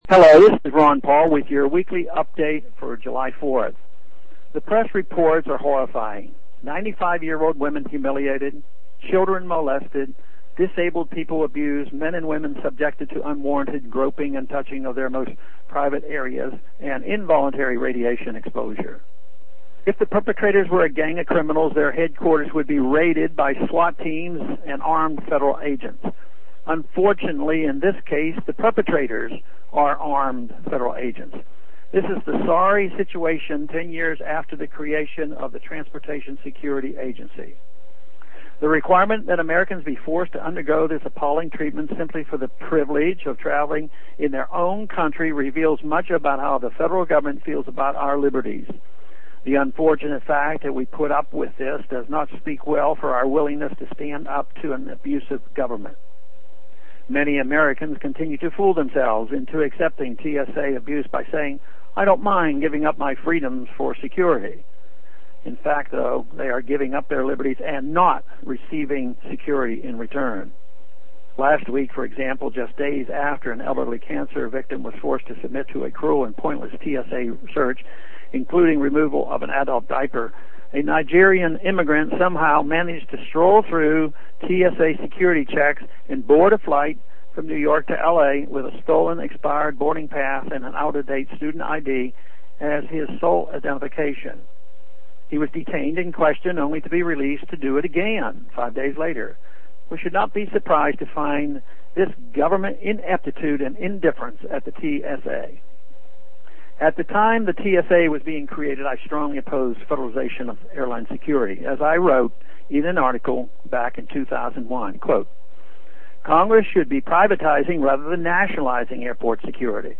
Listen to Rep. Ron Paul deliver this address.